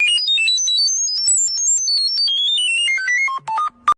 [SOUND] Beep Boop.ogg